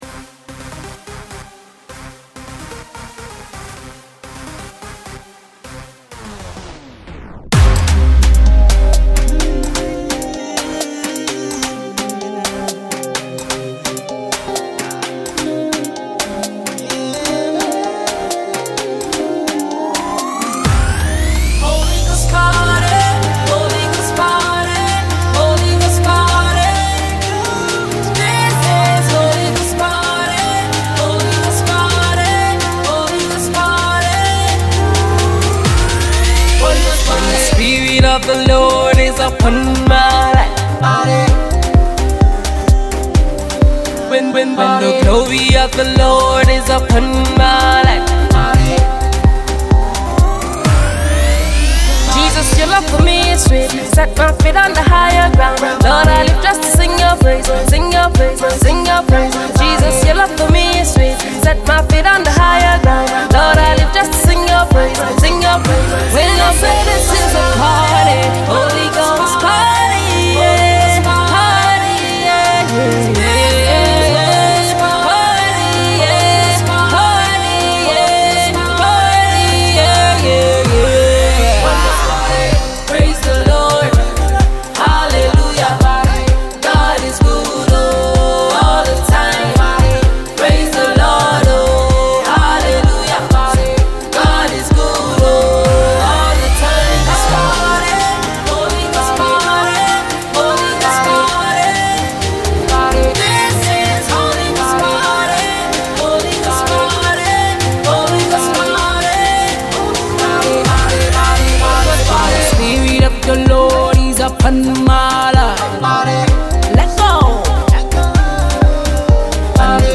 two girls group